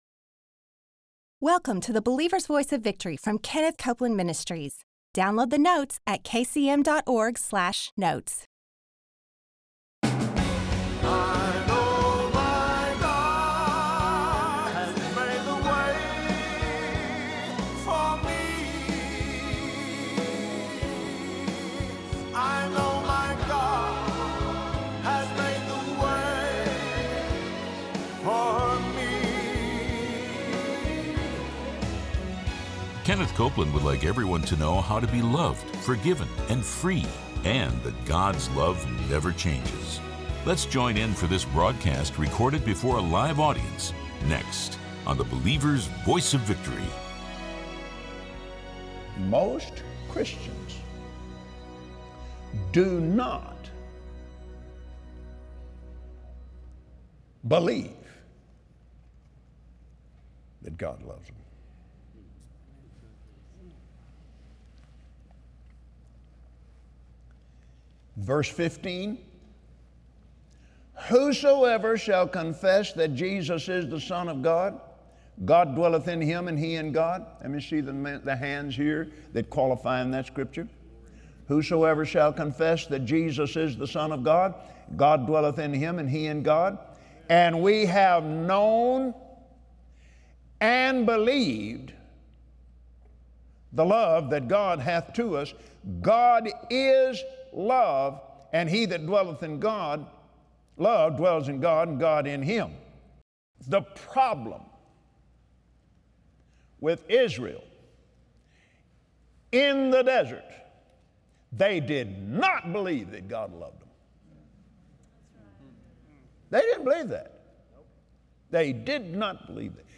Believers Voice of Victory Audio Broadcast for Tuesday 05/30/2017 Watch Kenneth Copeland on the Believer’s Voice of Victory broadcast share how God created His commandments from a place of love to protect you. When you follow God’s Word, He will manifest Himself to you, and blessings will overtake you!